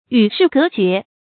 与世隔绝 yǔ shì gé jué 成语解释 与社会上的人们隔离；断绝来往。